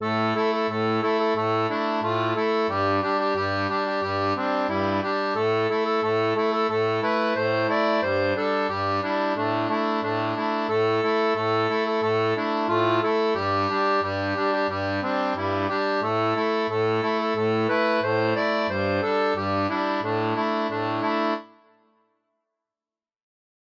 Chant de marins